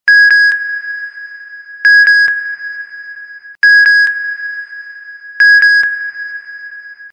notify-bell_24829.mp3